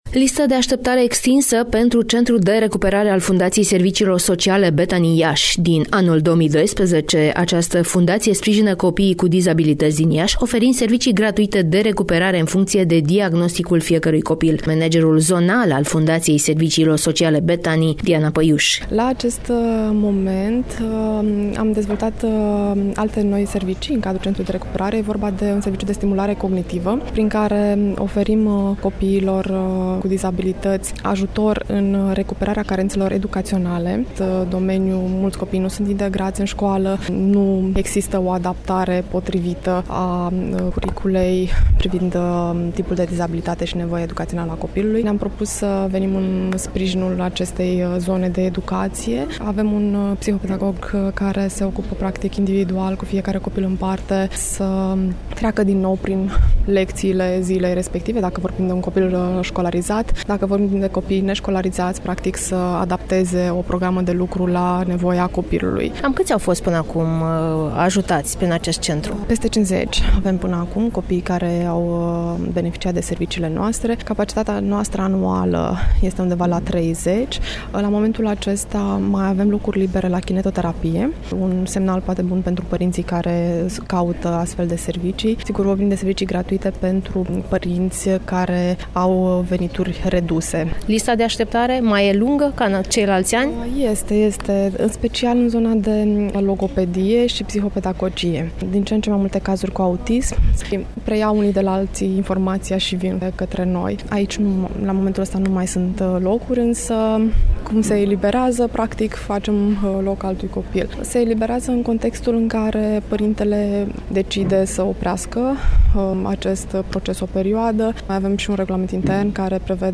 Interviu(IAȘI): Solicitări foarte multe pentru serviciile centrului de recuperare al Fundației Bethany